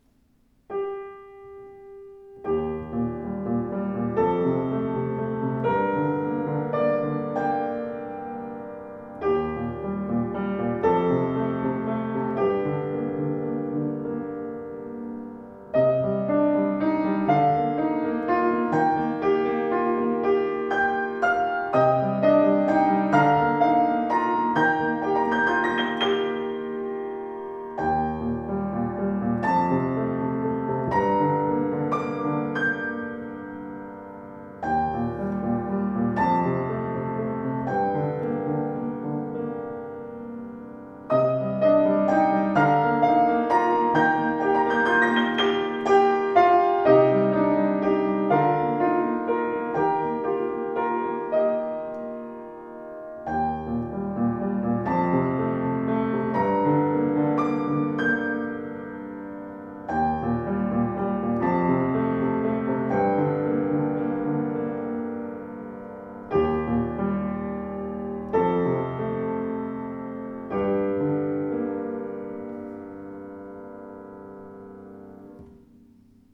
Erstaunlich voller und schöner Klang (das Modell 116 hat 118 cm Bauhöhe!) mit ausgewogenem Spielgefühl macht berührendes Musizieren möglich.
Klaviere
Foerster-Erle-Klang.mp3